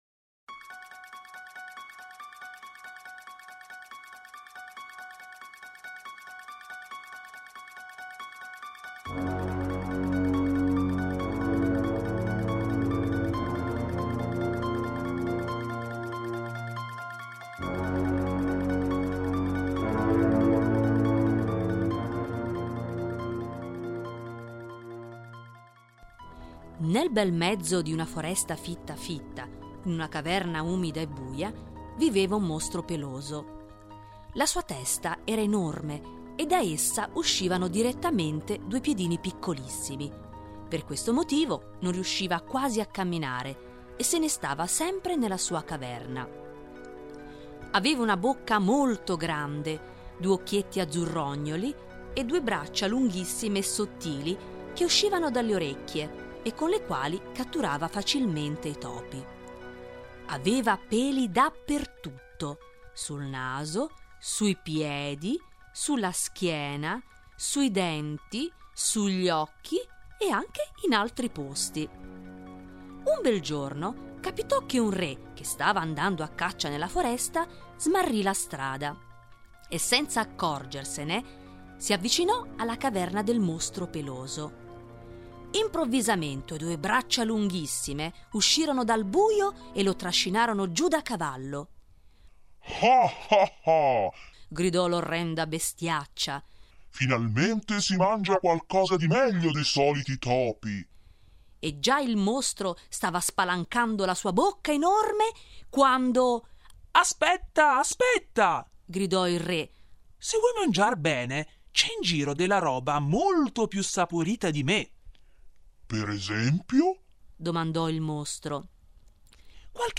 ma per tutti i grandi e piccini che amano ascoltare le storie dei “mostri”.